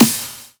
Scatta_Snare.wav